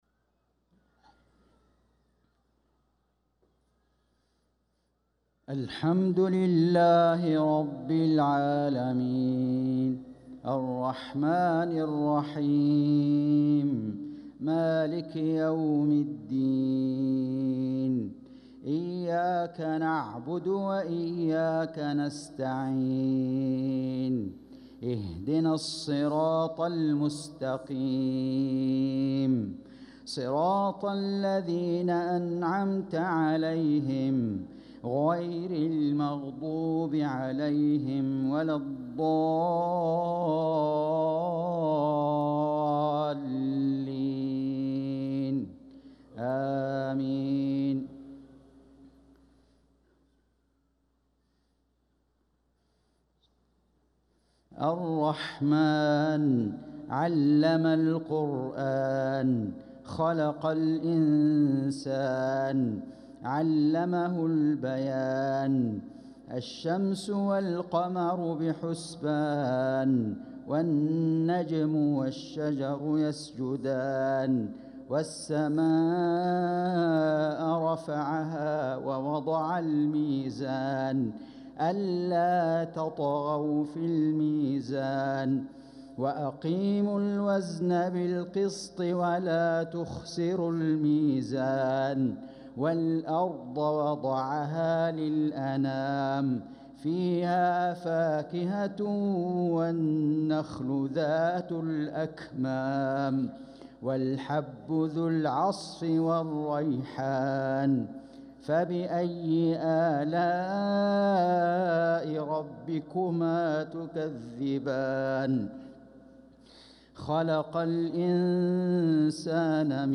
صلاة العشاء للقارئ فيصل غزاوي 9 ربيع الأول 1446 هـ
تِلَاوَات الْحَرَمَيْن .